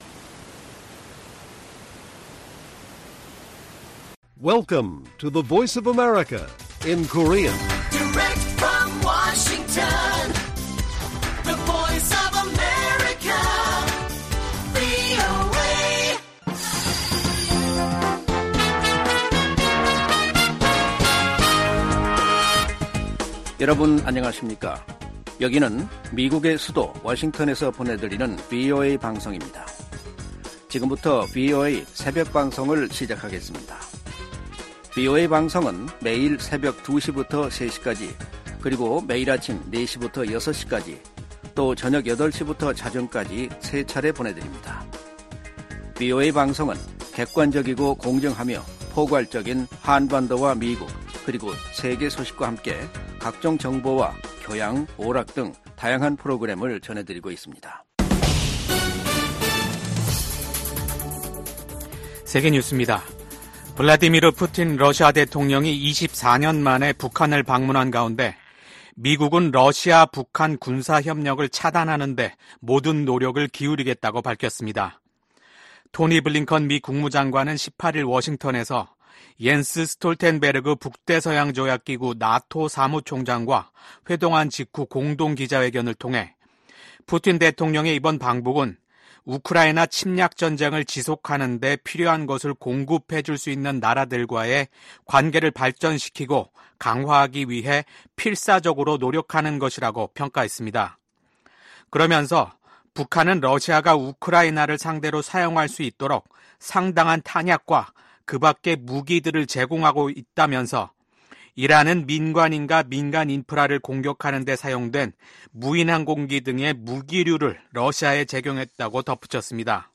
VOA 한국어 '출발 뉴스 쇼', 2024년 6월 20일 방송입니다. 김정은 북한 국무위원장과 블라디미르 푸틴 러시아 대통령이 오늘, 19일 평양에서 정상회담을 갖고 포괄적 전략 동반자 협정에 서명했습니다. 미국 백악관은 푸틴 러시아 대통령이 김정은 북한 국무위원장에게 외교가 한반도 문제 해결의 유일한 해법이라는 메시지를 전달해야 한다고 촉구했습니다.